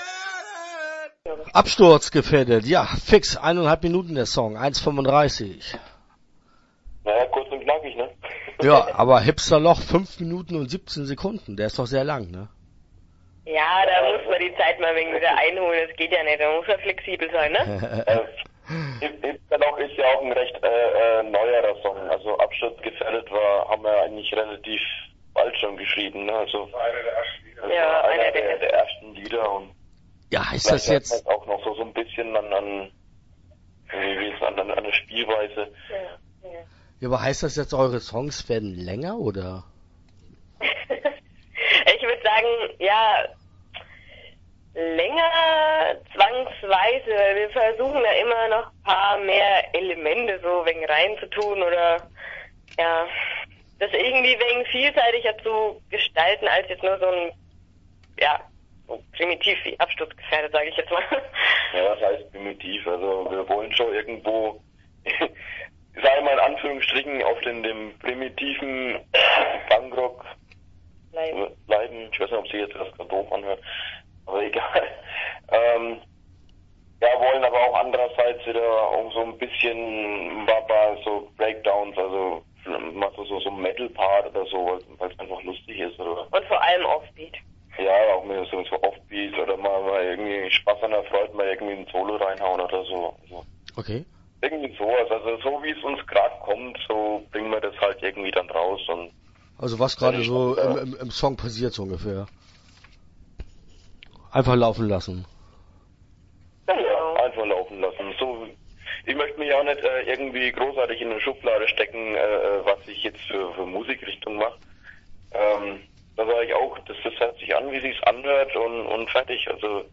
Absturzgefährdet - Interview Teil 1 (13:37)